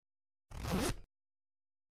На этой странице собраны разнообразные звуки застежек и фермуаров от женских кошельков, сумок и других аксессуаров.
Молния на штанах